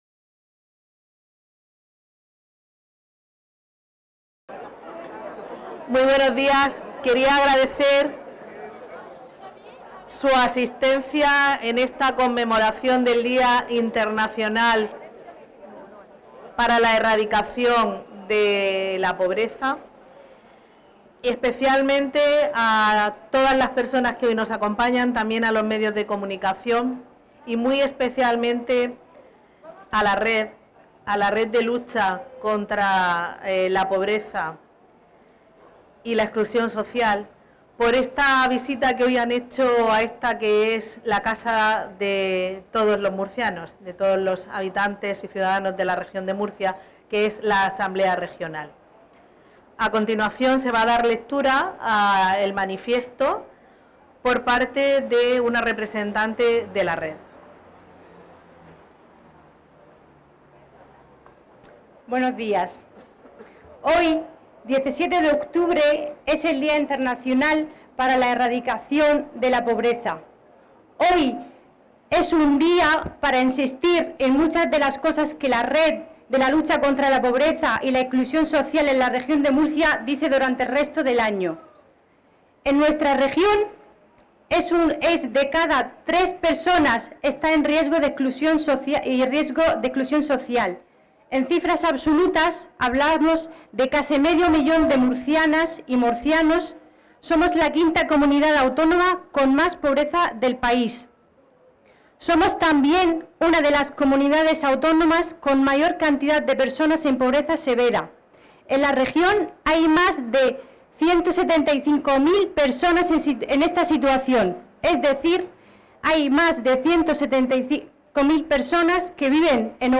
• Lectura del Manifiesto con motivo del Día Internacional para la Erradicación de la Pobreza
Lectura del Manifiesto de la EAPN-RM